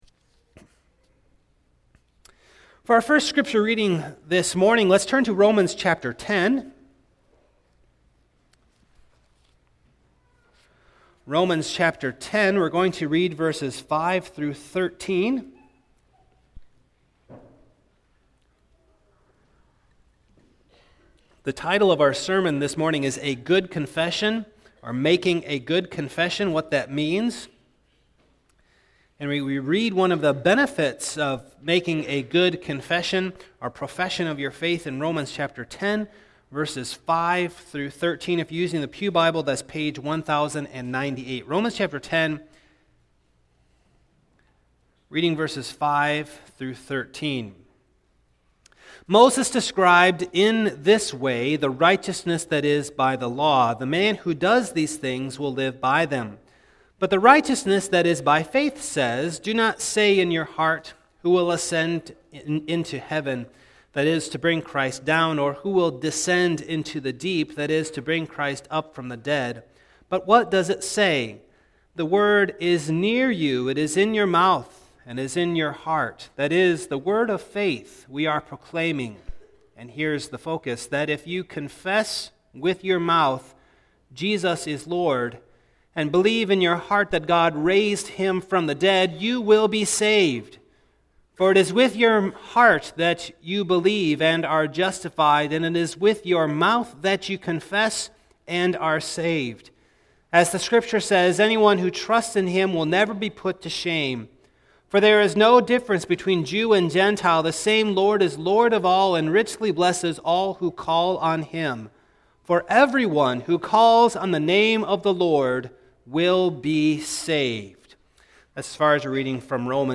Passage: Luke 9:18-20 Service Type: Morning